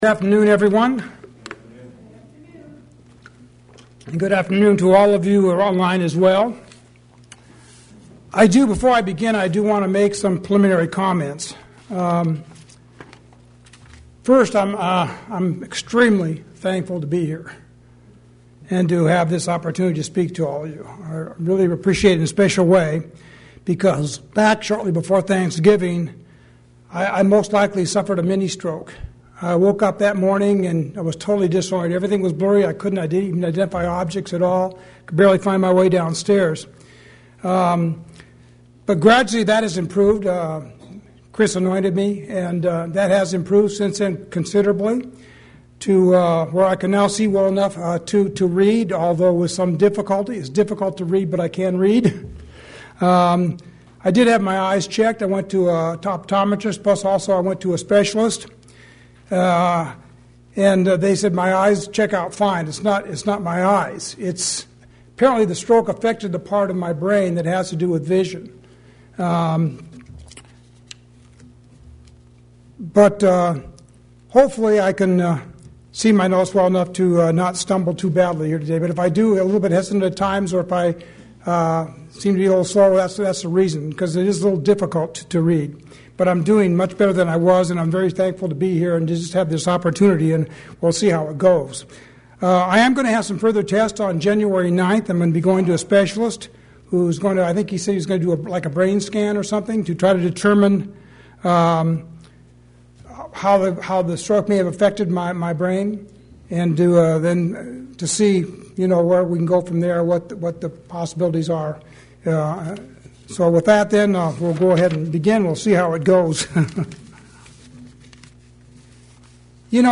This is Part 1 of a series of 6 sermons I will give on the life of Abraham.